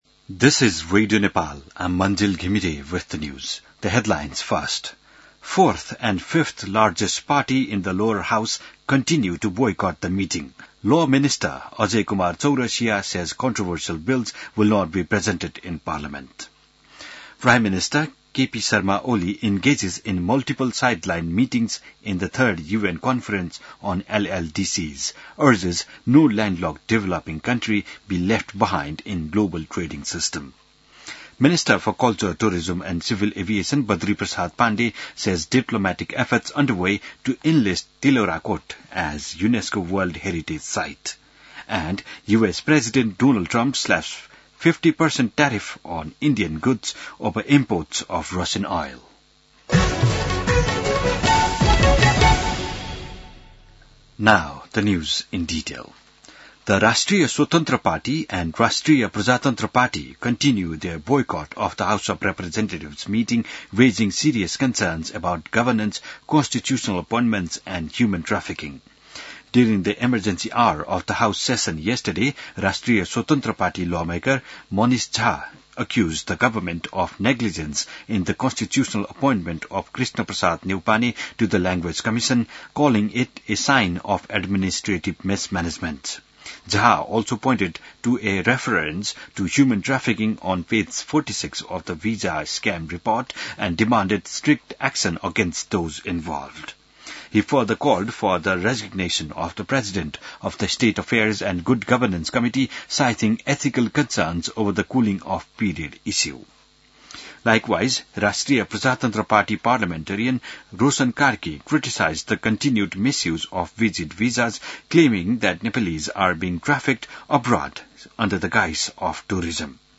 बिहान ८ बजेको अङ्ग्रेजी समाचार : २२ साउन , २०८२